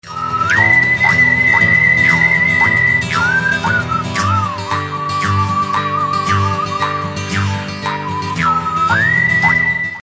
Guitar Version